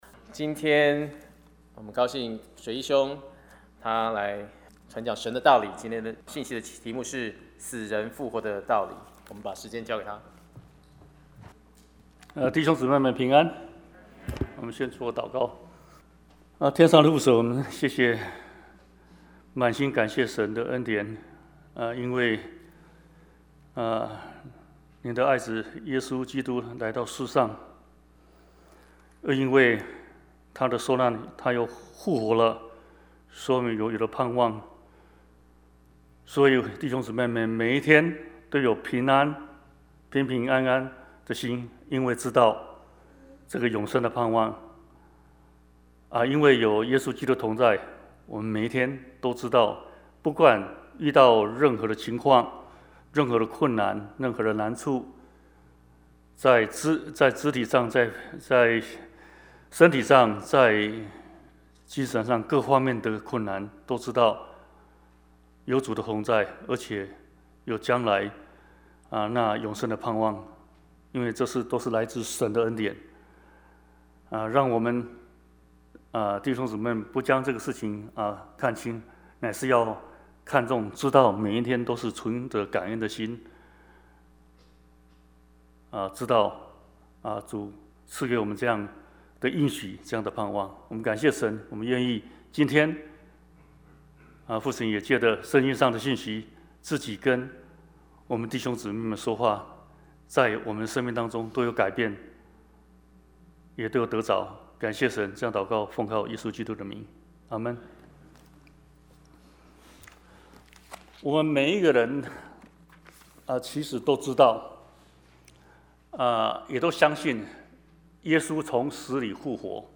Bible Text: Acts 24:1-25 | Preacher